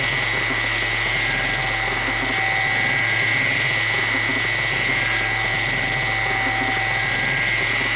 tesla-turret-beam.ogg